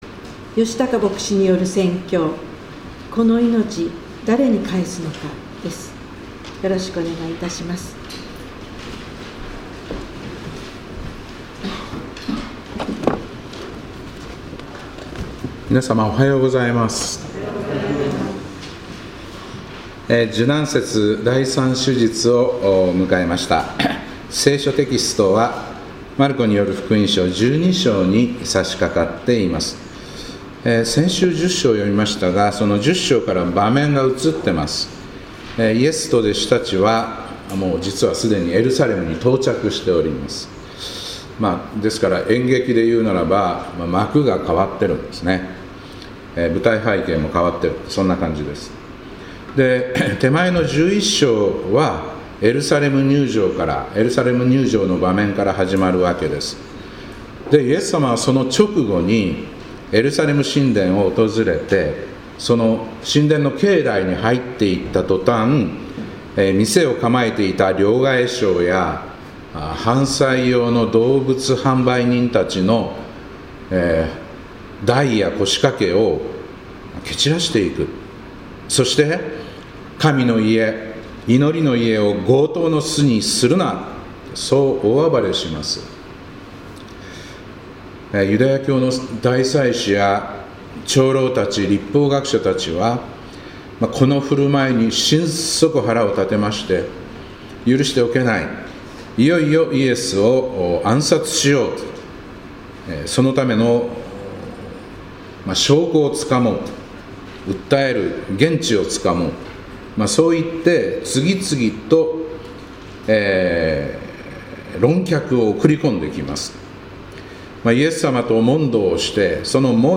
2026年3月8日礼拝「このいのち、誰に返すのか」